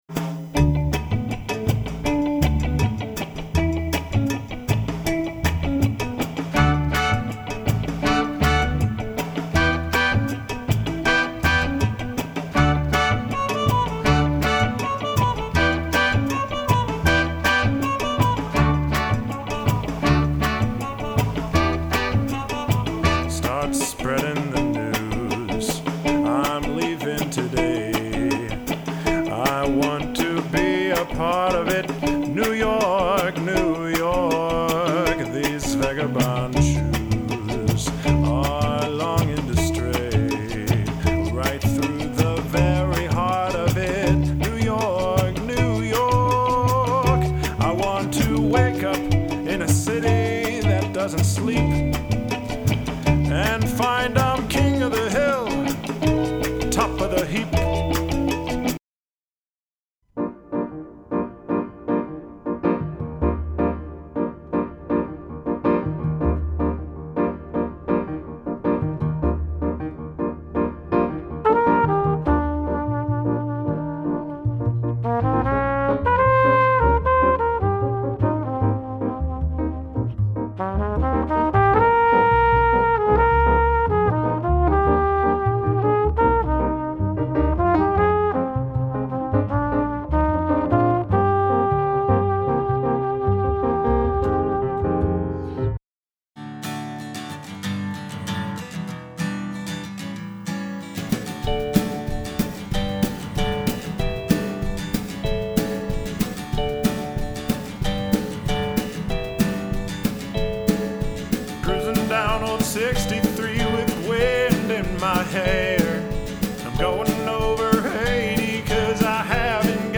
This track has several short selections of different grooves.
It is consistent, steady, and in all of these examples 4 beats to a measure. 1,2,3,4,1,2,3,4, etc. Listen through the music and find that pulse and move your body with it.
There are a few tracks without drums on them, so new drummers, it’s up to you to be the drums and play a part that fits in with the rest of the music.